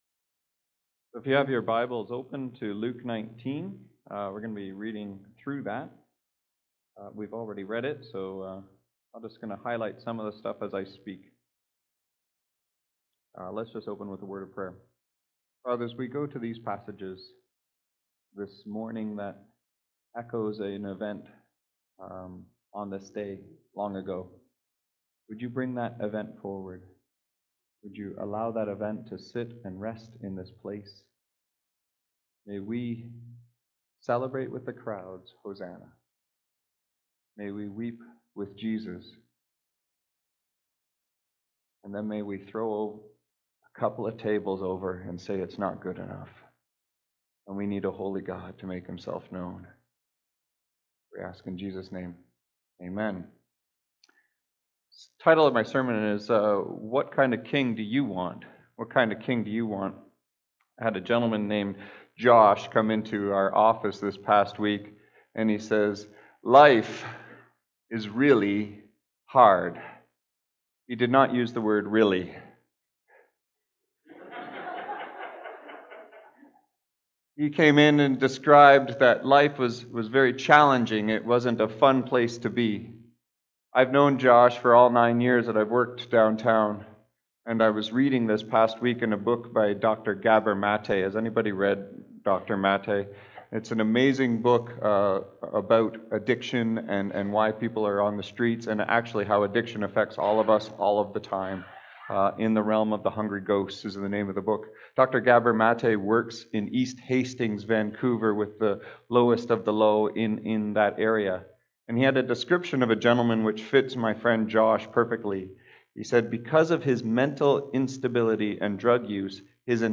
Weekly Sermons What Kind of King Do You Want?